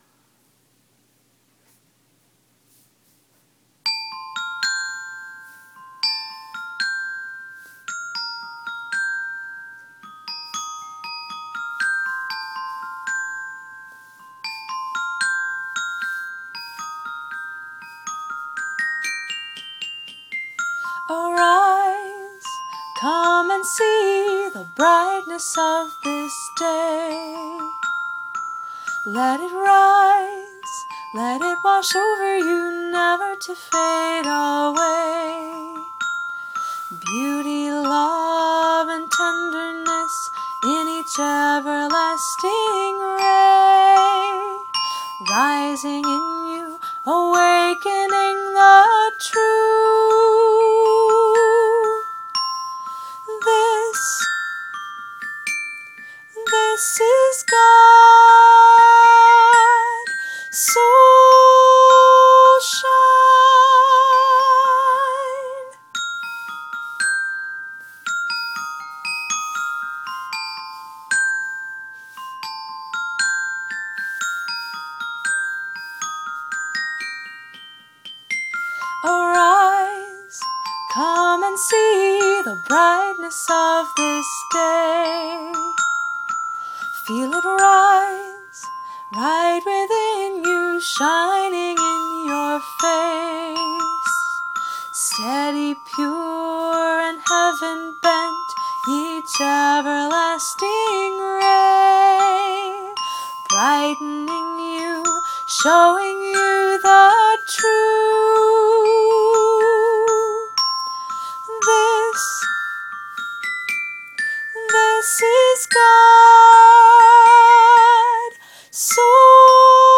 played on the glockenspiel